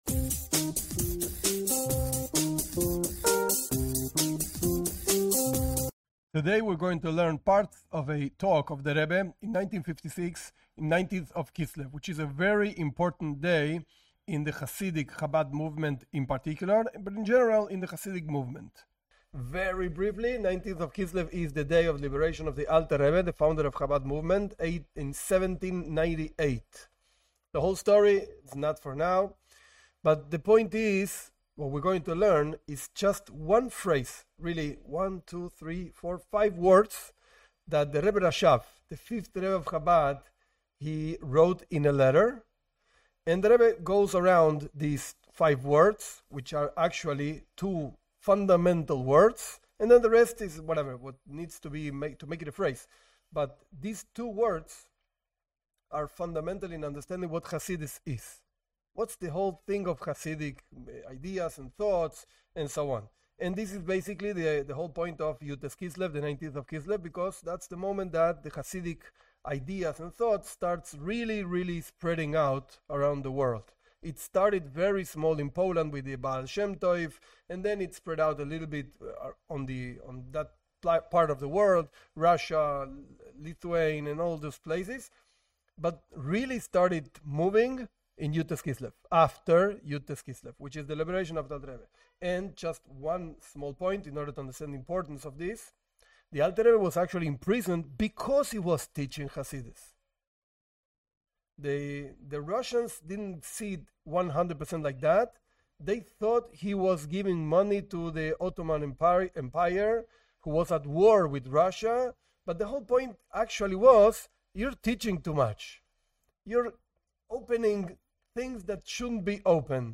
This class is a part of the talks of the Rebbe on the 19th of Kislev 1956.